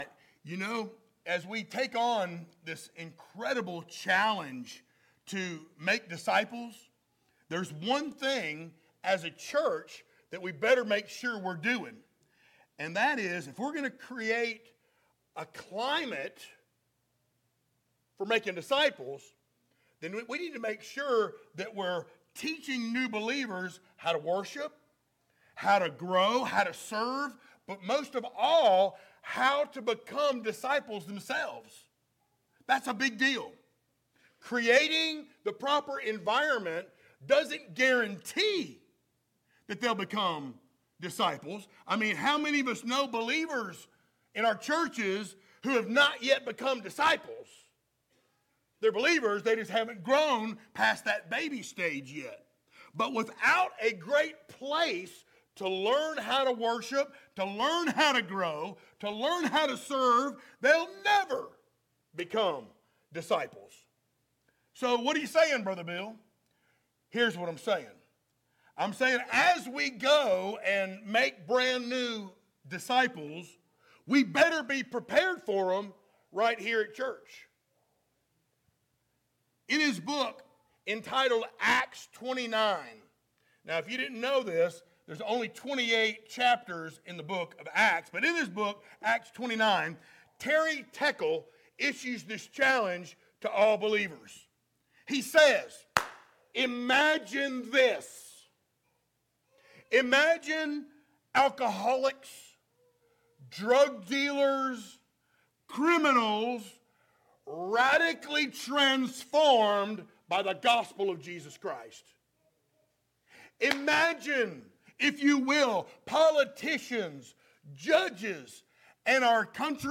Series: sermons
Acts 2:42-47 Service Type: Sunday Morning Download Files Notes « What’s It Gonna’ Take?